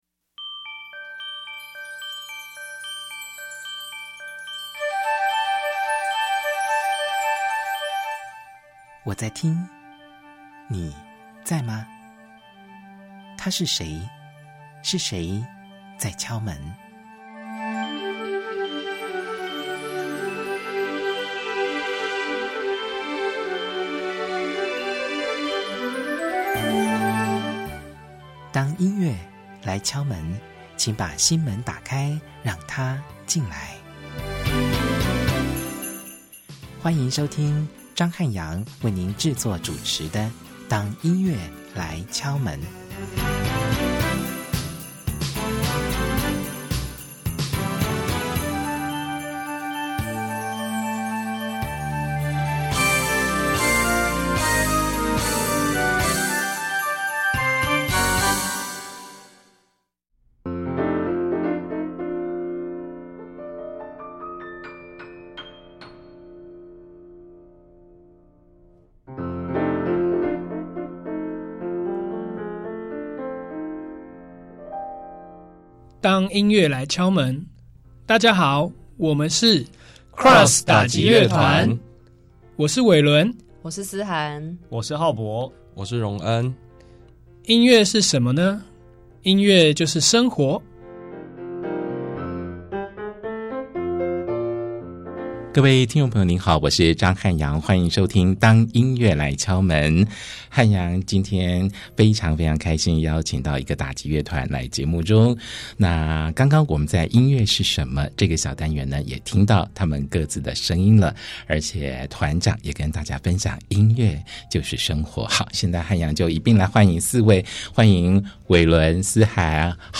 本集節目充滿爆笑的精采對話，歡迎收聽。